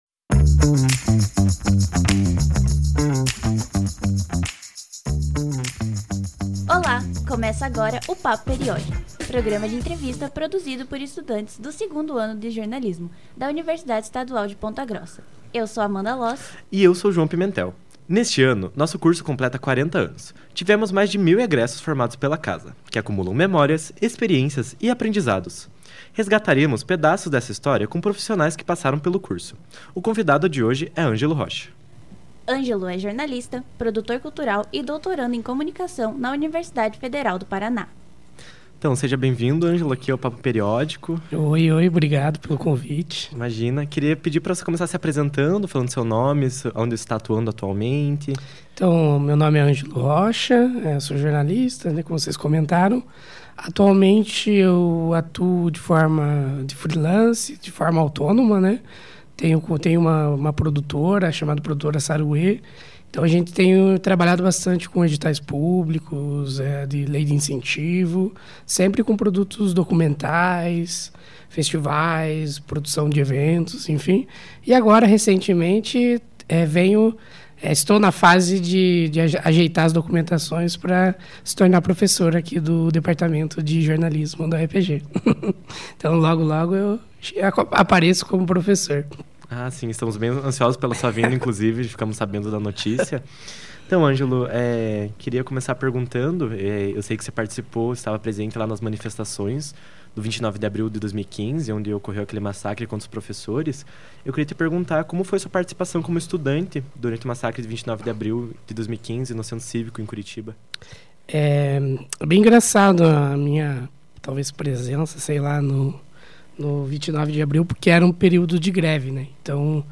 Na entrevista